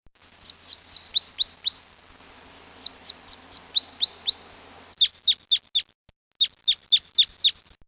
I usually find them by their tinny calls, as they move quickly in the high canopy, foraging for cones.
Red Crossbill
red_crossbill.mp3